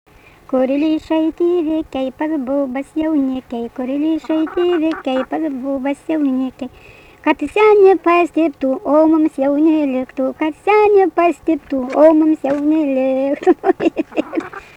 daina
Kašėtos
vokalinis